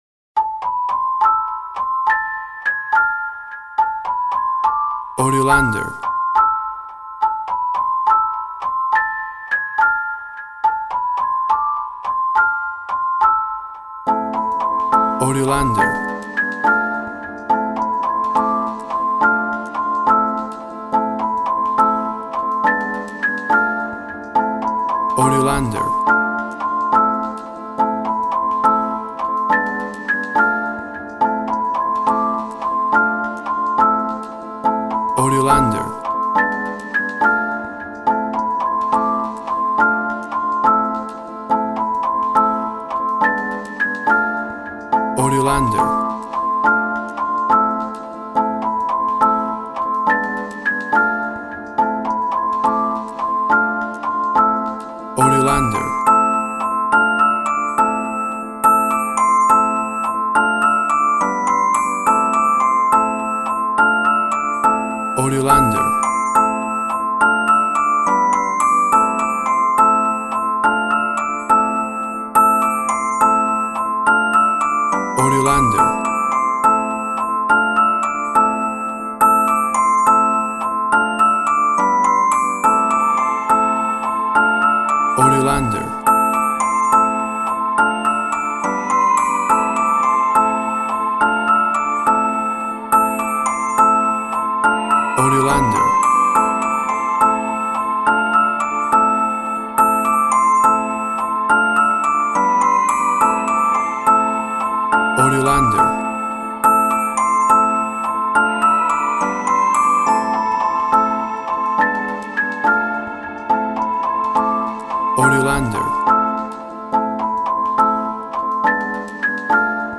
Tempo (BPM) 105